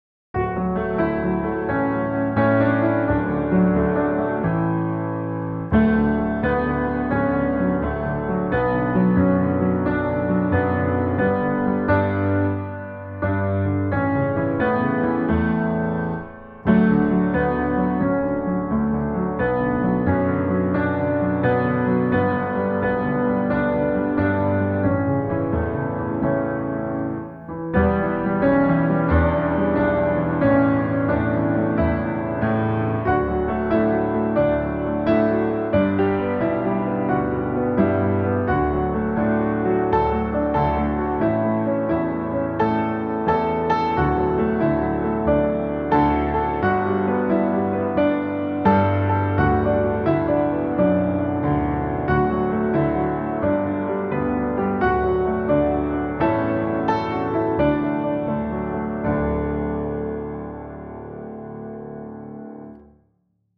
Pour A Little Love - Music in D no Vox mp3MP3088D